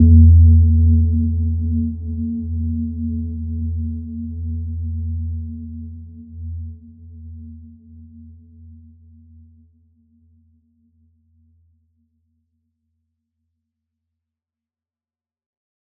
Gentle-Metallic-4-E2-f.wav